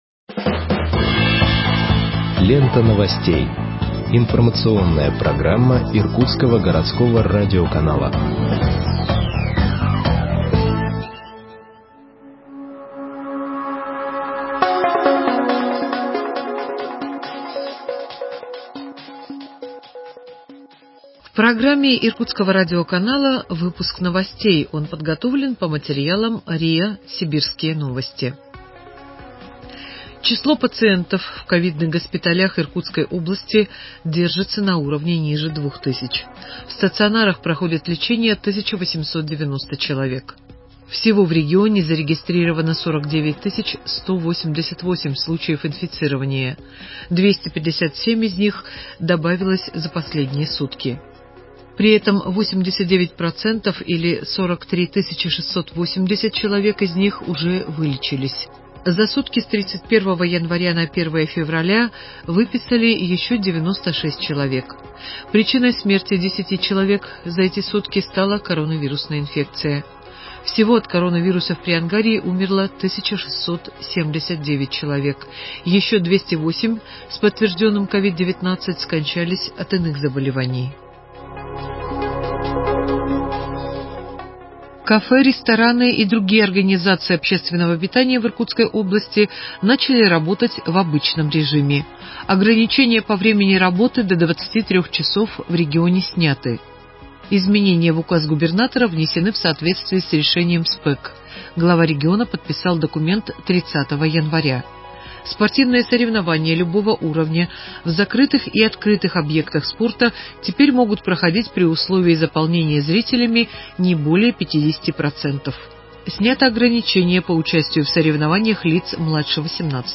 Выпуск новостей в подкастах газеты Иркутск от 02.02.2021 № 1